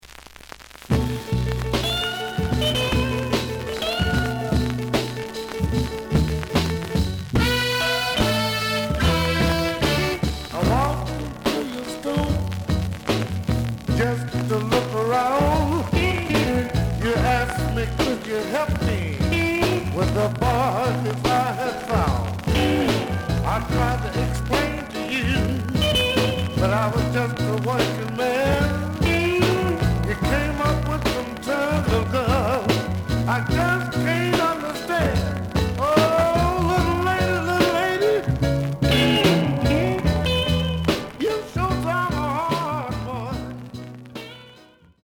The audio sample is recorded from the actual item.
●Format: 7 inch
●Genre: Blues